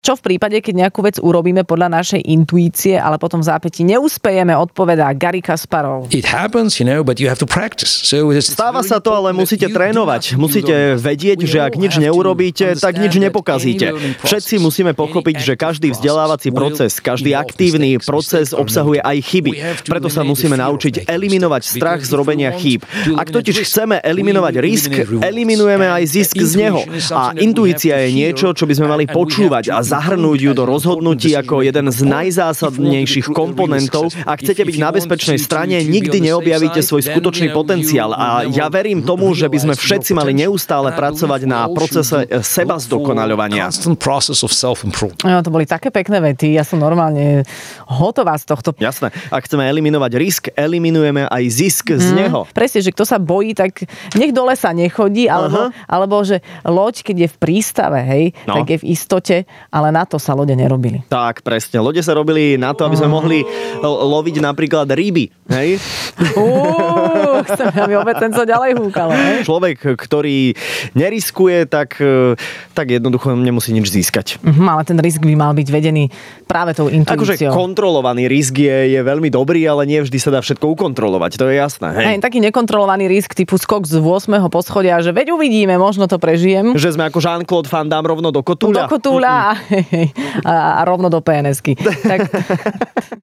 Gary Kasparov, šachový veľmajster, bol hosťom v Rannej šou s Adelou a Sajfom!